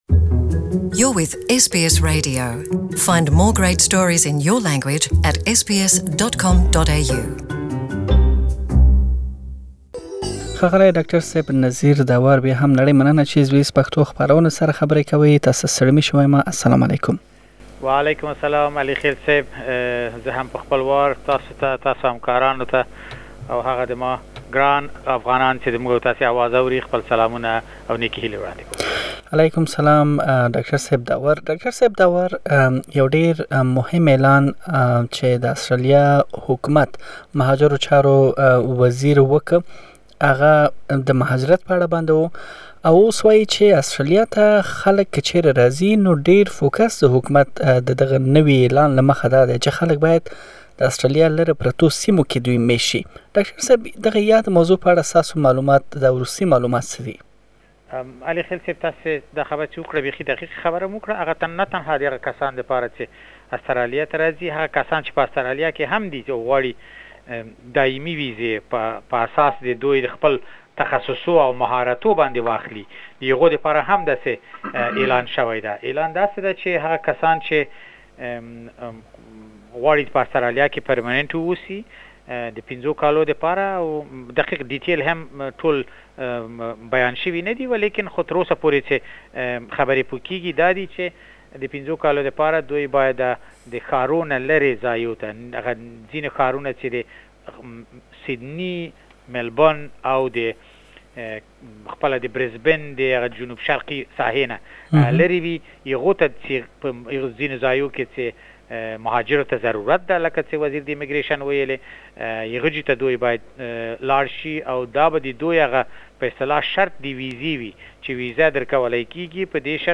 you can listen to the full interview in Pashto.